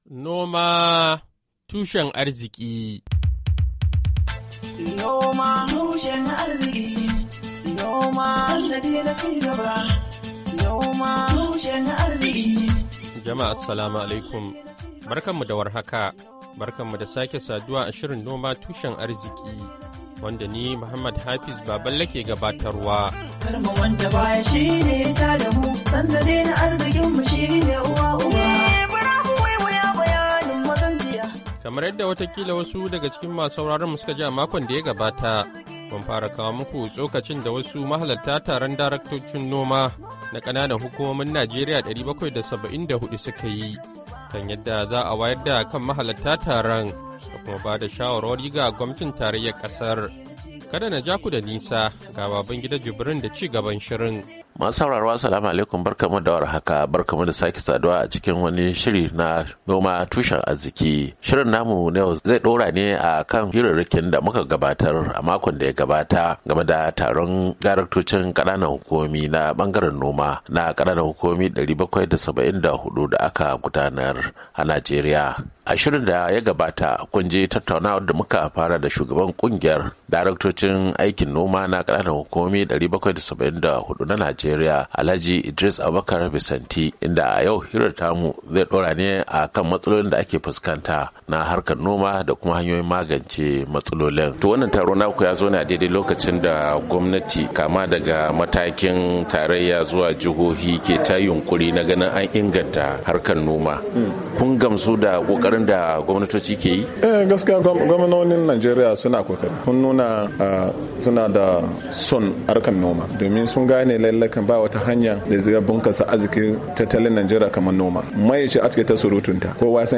Shirin Noma Tushen Arziki na wannan makon, zai kawo muku ci gaban tattaunawa da mahalarta taron daraktocin noma na kananan hukumomi 774 a Najeriya, inda suka tattauna yadda za'a wayar da kan mahalarta taron da kuma ba da shawara ga gwamnatin tarayyar kasar.